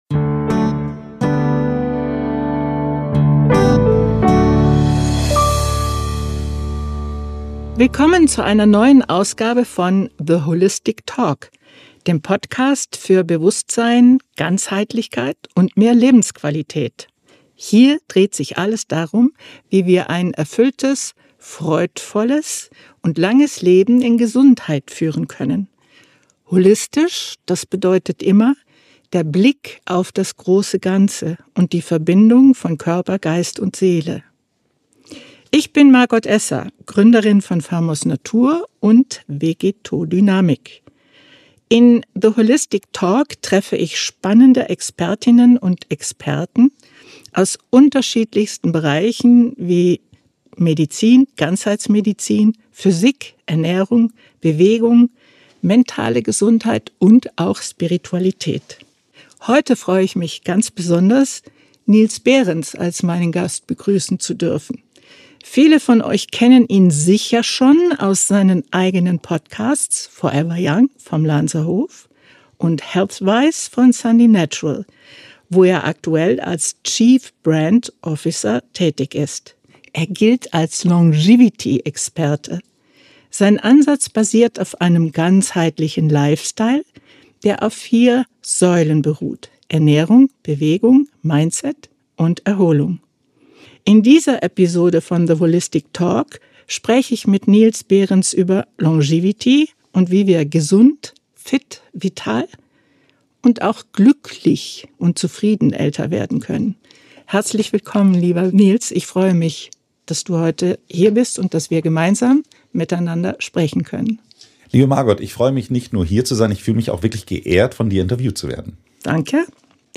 Im Fokus stehen ganzheitliche Gesundheit und die tiefere Verbindung von Körper, Geist und Seele. Dabei sind die vier Säulen von Longevity: Ernährung, Bewegung, Erholung und Mindset essenziell für ein gesundes, langes und glückliches Leben. Ein inspirierendes Gespräch rund um neuste wissenschaftliche Erkenntnisse im Jahr der Quantenphysik, praktische Tipps sowie die besondere Bedeutung von Mindset und Spirituali-tät.